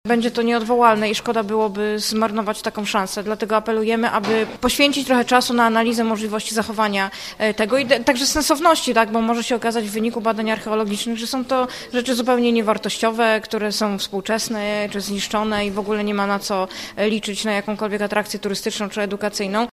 Radni nie wiedzą ile inwestycja mogłaby kosztować, ale ich zdaniem Gorzów nie powinien zmarnować takiej szansy. Mówi szefowa klubu Kocham Gorzów radna Marta Bejnar – Bejnarowicz: